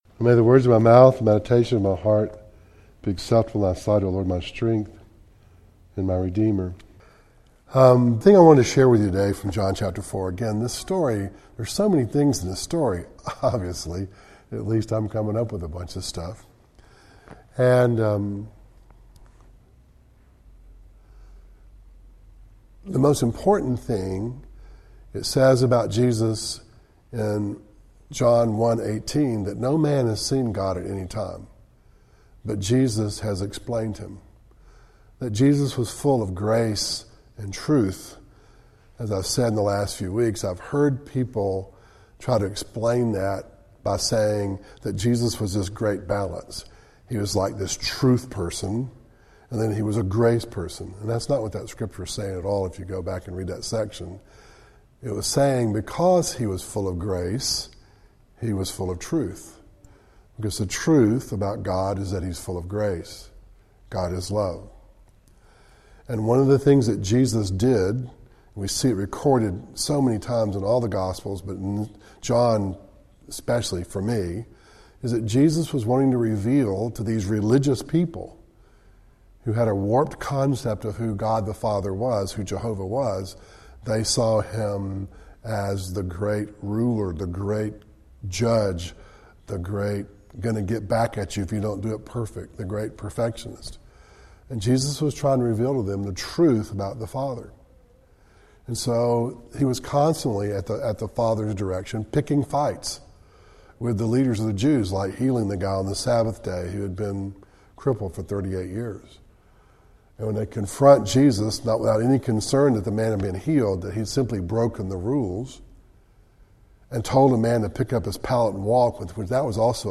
Matthew 16:28-17:2 Service Type: Devotional